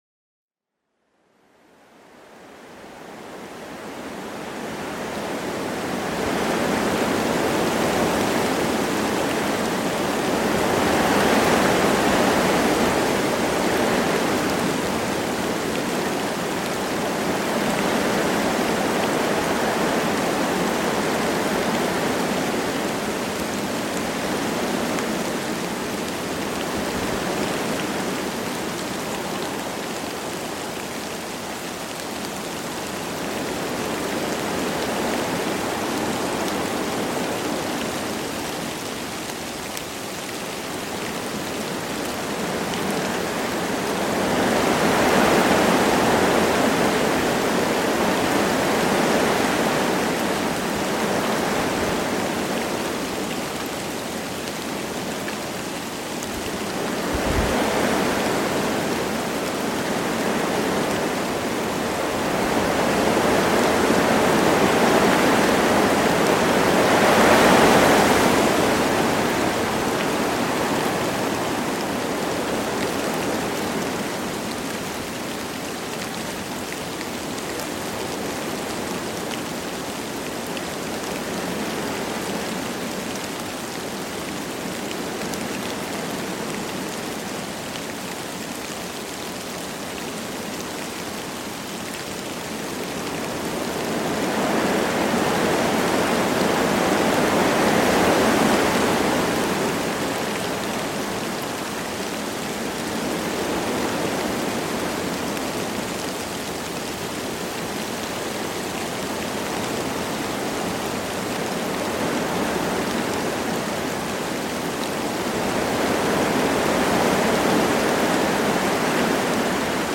PERFEKTE ENTSPANNUNG: Die Balance-Formel Wind + Regen